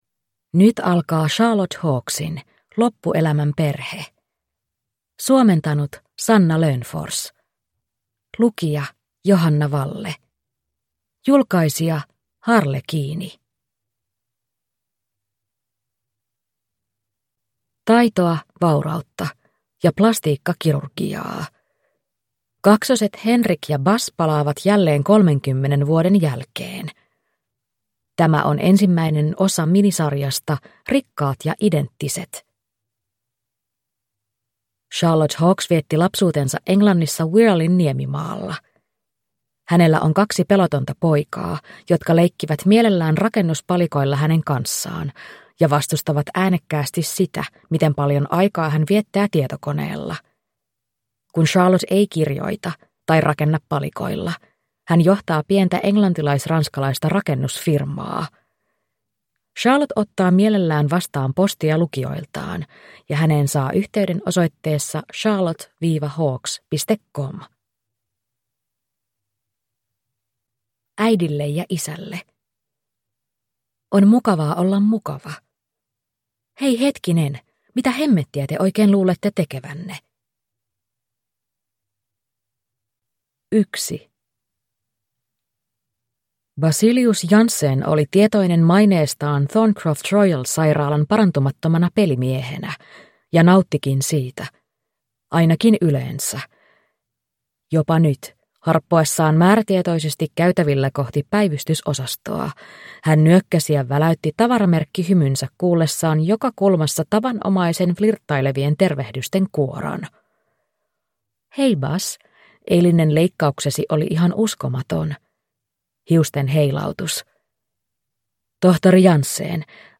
Loppuelämän perhe (ljudbok) av Charlotte Hawkes